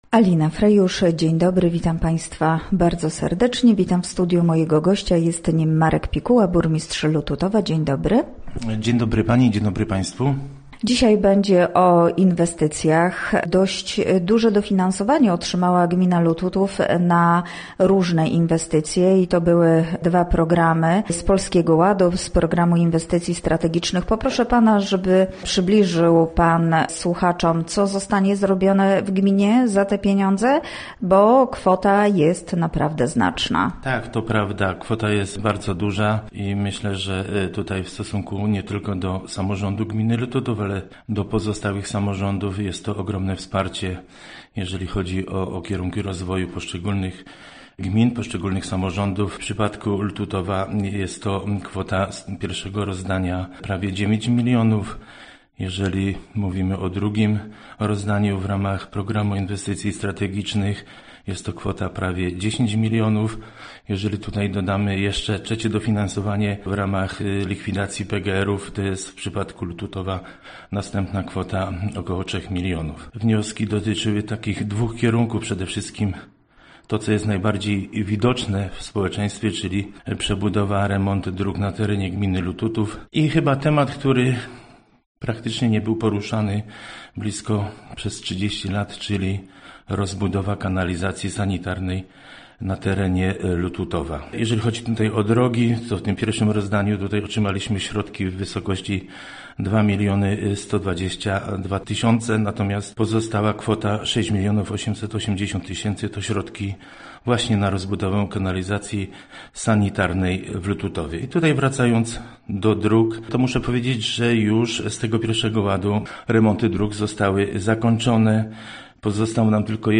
Gościem Radia ZW był Marek Pikuła, burmistrz Lututowa